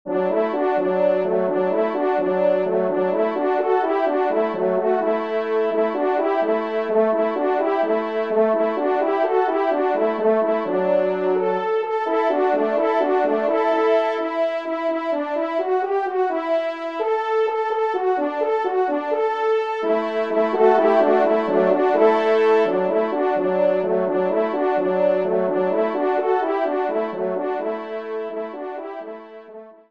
Genre :  Divertissement pour Trompes ou Cors en Ré
2e et 3° Trompe